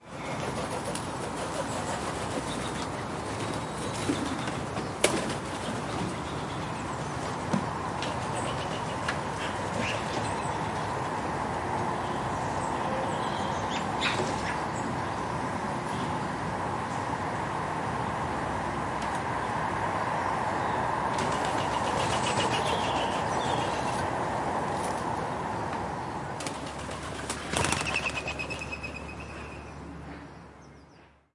快速的翅膀
描述：这个声音是用雨伞创造的。在后期制作中，声音是分层的，音高是增加和减少的。这种声音是一种相对快速的翅膀拍打声。用Zoom H6录音机录制这个声音
标签： 翅膀 翅膀 动物 拍打 天空 飞行 鸽子 昆虫 性质 OWI 蝙蝠
声道立体声